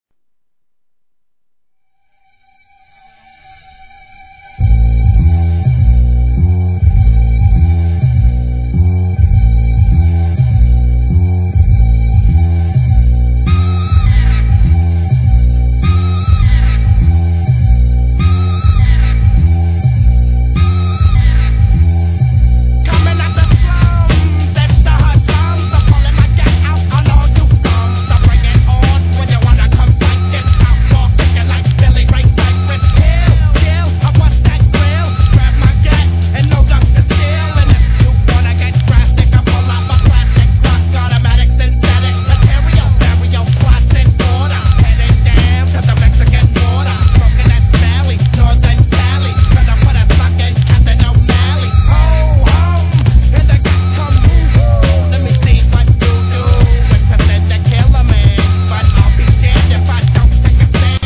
HIP HOP/R&B
地を這うベースライン、疾走感を煽るドラム、煙々しいサウンド、